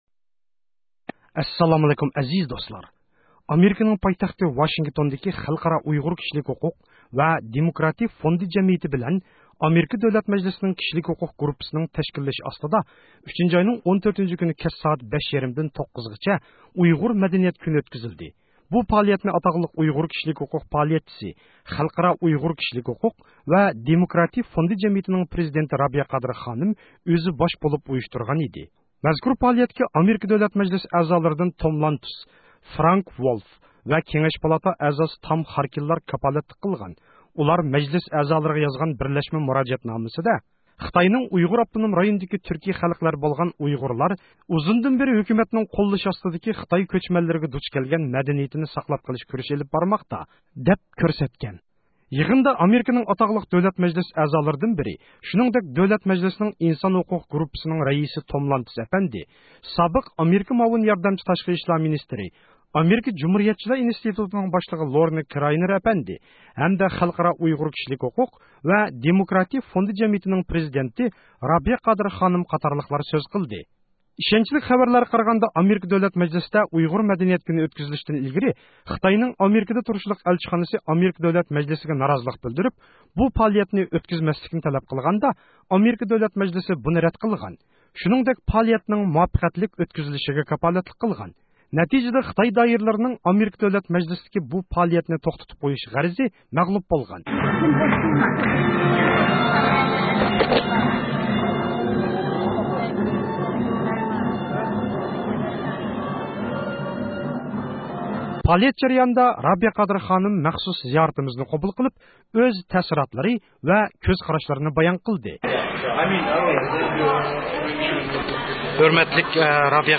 پائالىيەت جەريانىدا رابىيە قادىر خانىم مەخسۇس زىيارىتىمىزنى قوبۇل قىلىپ، ئۆز تەسىراتلىرى ۋە كۆز قاراشلىرىنى بايان قىلدى.